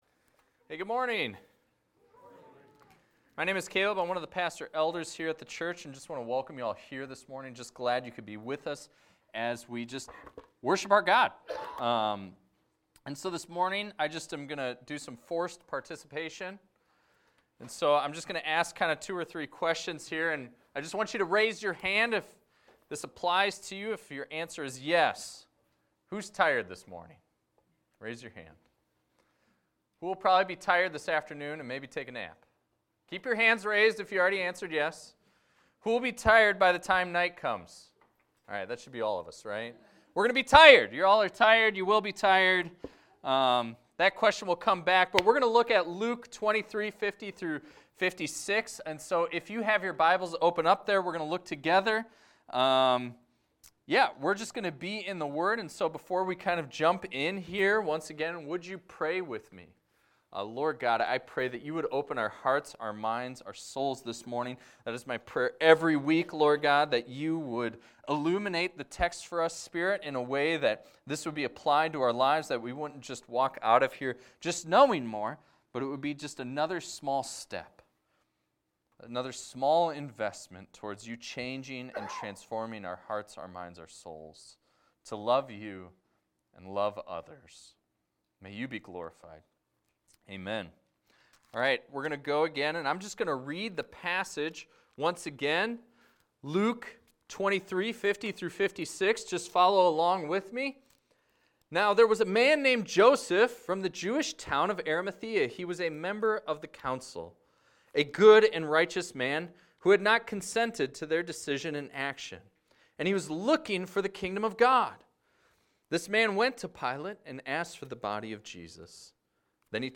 This is a recording of a sermon titled, "A Tomb Fit for a King."